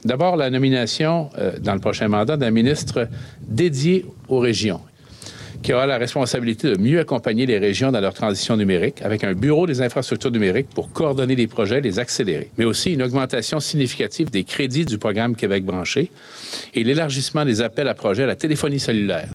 À Havre-Aubert aujourd’hui, il s’est engagé à investir 200 millions de dollars de plus pour étendre la couverture cellulaire et l’internet haute vitesse dans les régions mal desservies du Québec.
Le Parti libéral aimerait que, d’ici 2020, tous les Québécois aient accès à l’internet haute vitesse. Philippe Couillard explique comment il compte y arriver.